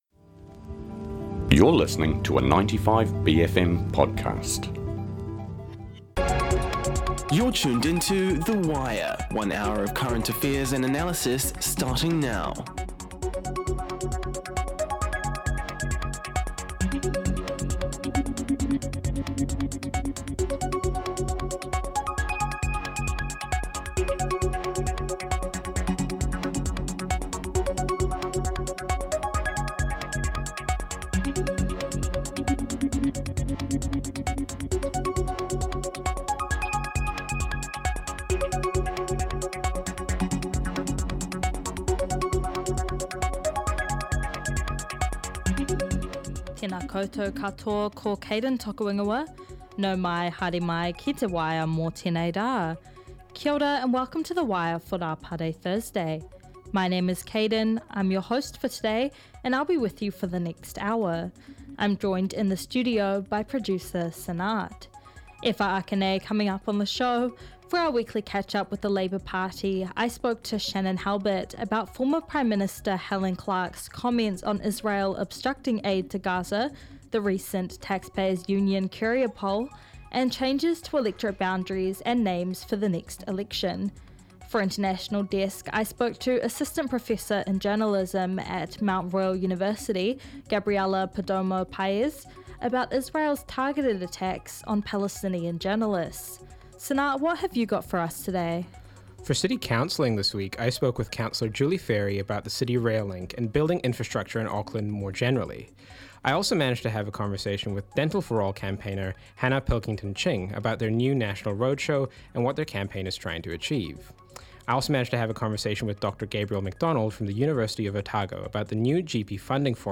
A couple of weeks ago in our weekly catch-up with the ACT Party’s Simon Court, we discussed multiple countries moving to formally recognise Palestinian Statehood, and what New Zealand’s plans were in this regard.